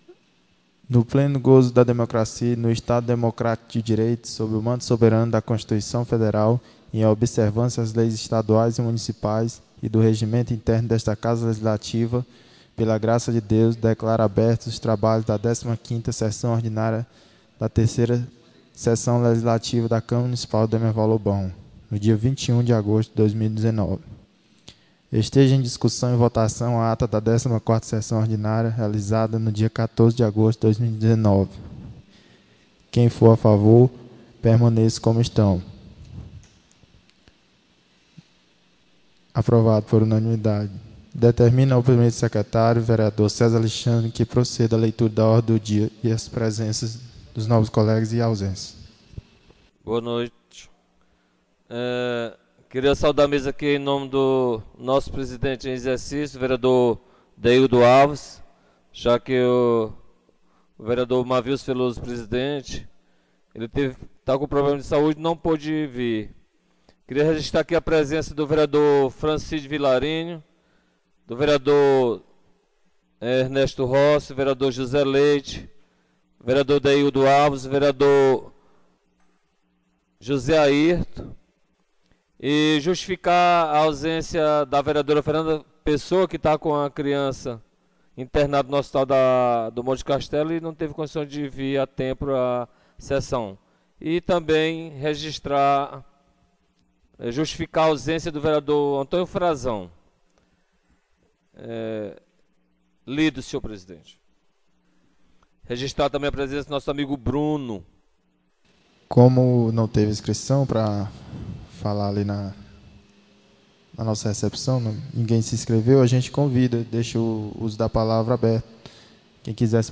15ª Sessão Ordinária 21 de Agosto